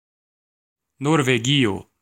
Ääntäminen
Vaihtoehtoiset kirjoitusmuodot (vanhahtava) Norroway Synonyymit Norwegia Ääntäminen US UK : IPA : /ˈnɔː.weɪ/ US : IPA : /ˈnɔɹ.weɪ/ Lyhenteet ja supistumat (laki) Nor.